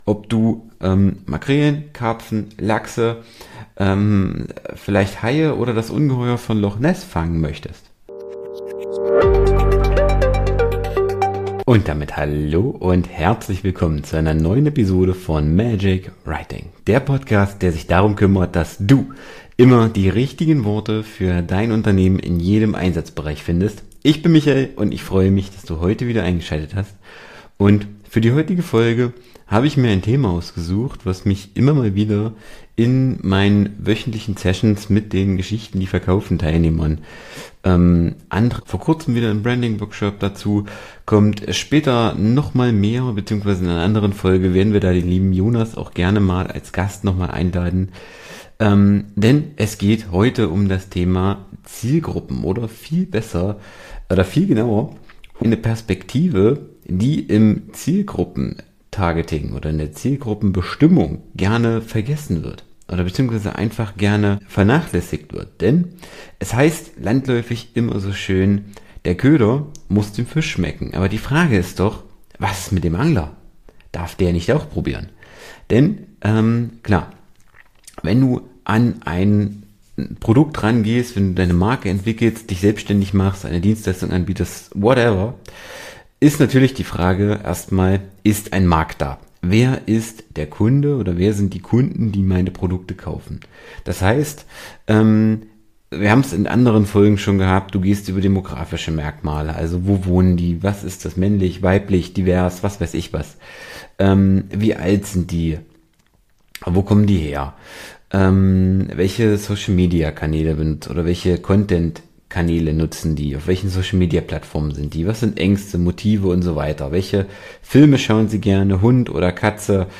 Dieser Podcast unterstützt Dich dabei, genau das zu ändern und qualifizierte Leads zu generieren. Erlebe Einzel-Episoden und spannende Interviews mit Experten, die alle Aspekte von Copywriting, Content-Marketing und Storytelling beleuchten.